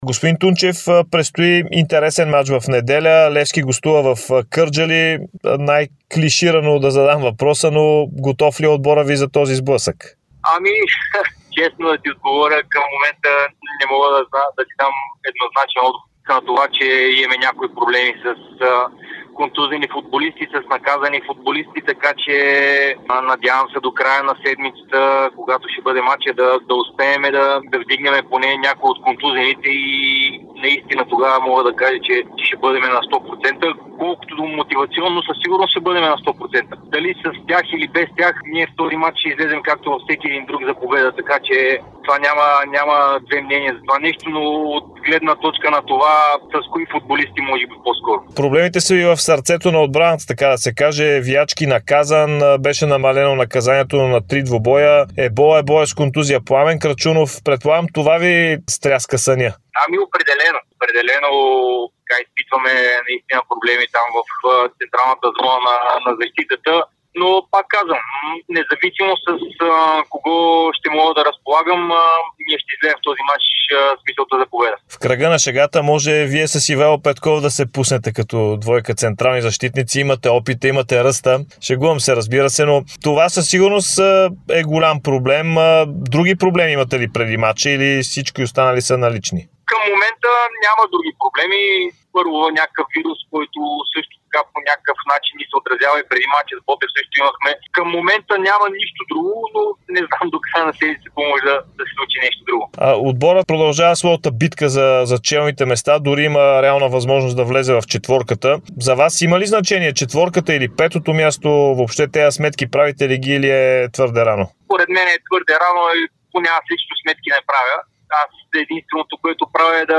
Треньорът на Арда – Александър Тунчев, даде интервю пред Дарик и dsport, в което коментира основно предстоящия мач с Левски. Според наставника има коренна промяна към добро съдийство.